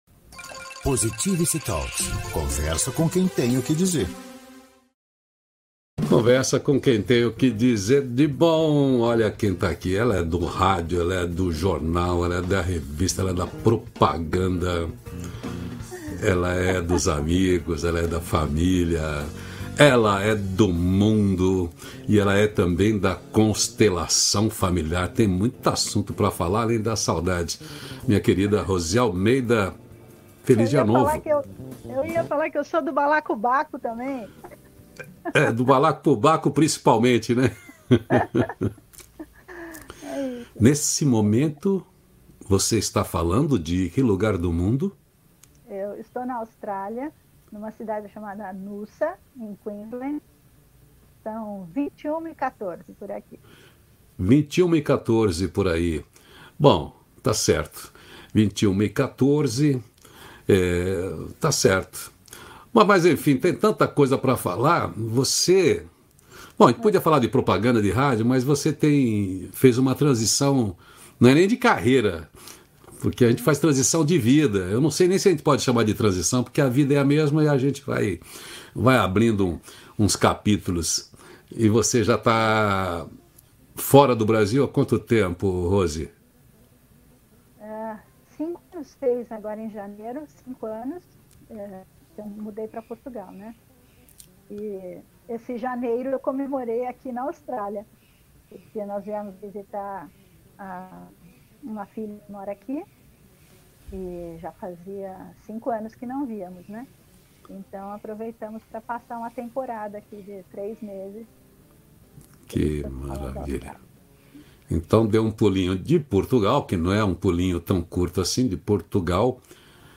Conversa com quem tem o que dizer.